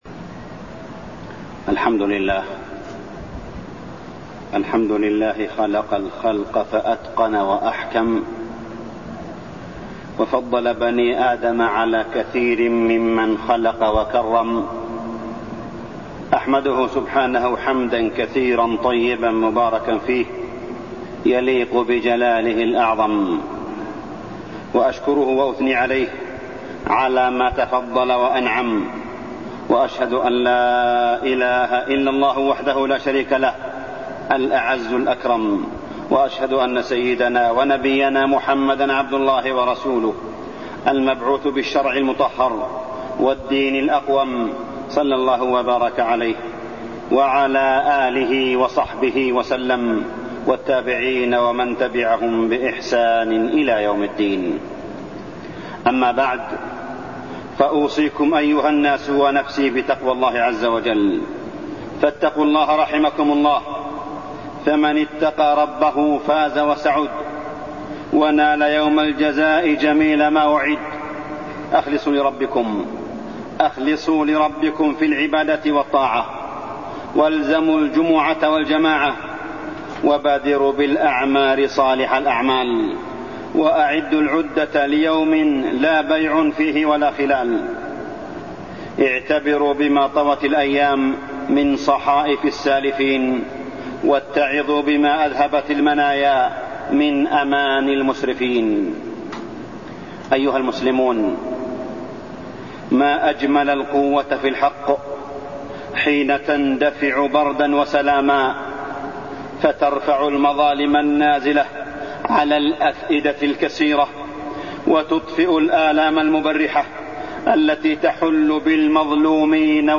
تاريخ النشر ٢ ربيع الأول ١٤٢٢ هـ المكان: المسجد الحرام الشيخ: معالي الشيخ أ.د. صالح بن عبدالله بن حميد معالي الشيخ أ.د. صالح بن عبدالله بن حميد القوة سر عز الأمة